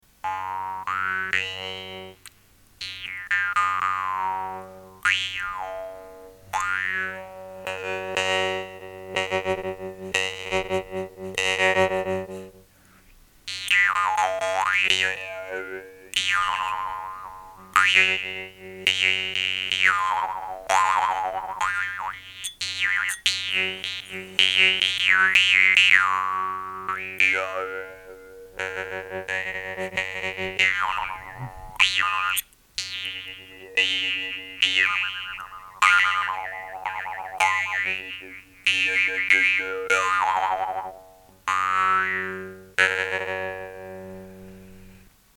Спектр тональностей:   Соль   Ля   Си     Вес: 22 гр.
Мягкий язычок, точно выверенный зазор между язычком и деками, множество обертонов, красивый мелодичный звук, чувствительность к дыханию и к любого рода артикуляции - все это будет интересно и начинающим и профессионалам.
nepal_oak.mp3